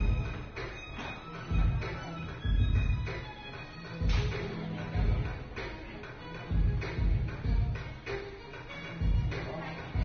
开场舞《舞力觉醒》